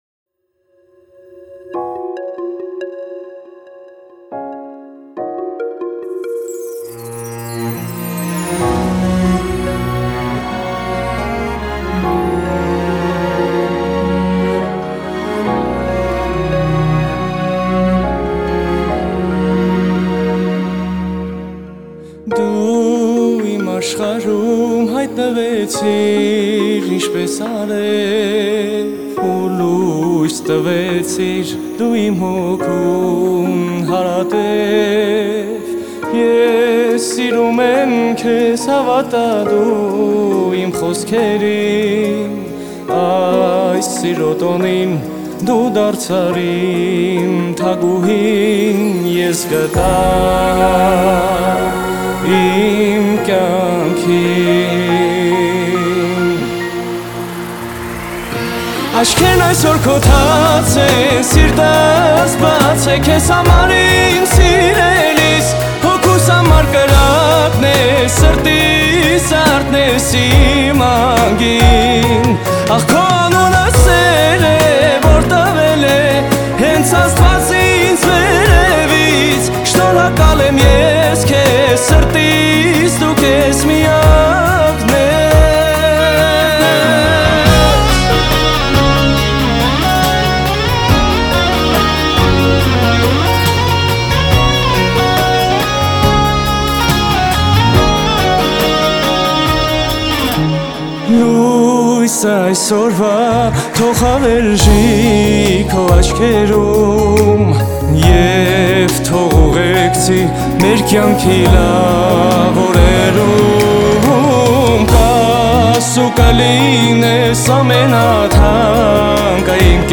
Трек размещён в разделе Русские песни / Армянские песни.